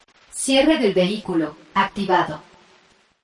Tesla Lock Sound Spanish Mexican Woman
Spanish female voice saying
with a mexican accent
(This is a lofi preview version. The downloadable version will be in full quality)
JM_Tesla-Lock_Spanish-MEX_Woman_Watermark.mp3